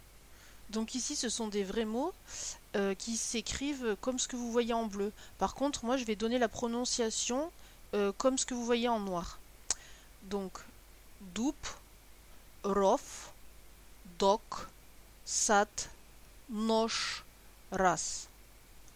4- A la fin des mots, les consonnes suivantes s’assourdissent:
voyelles-assourdies.mp3